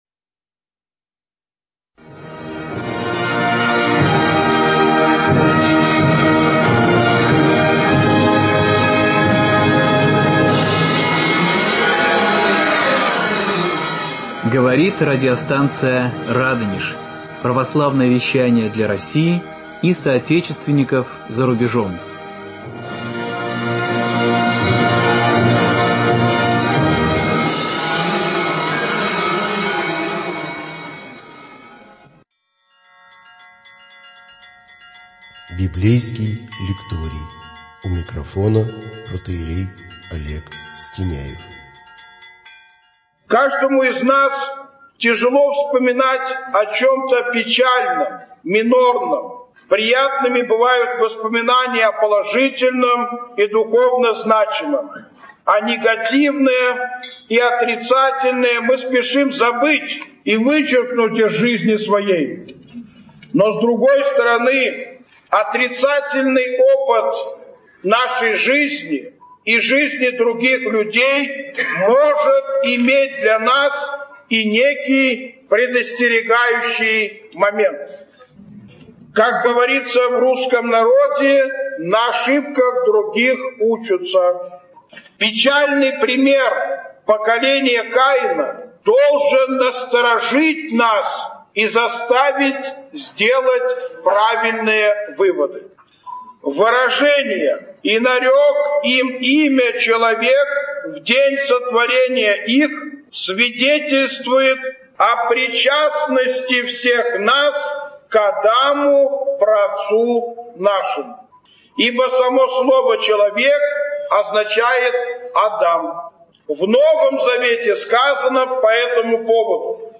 Беседа 5 часть 1 Эфир от 02.02.2025 19:00 | Радонеж.Ру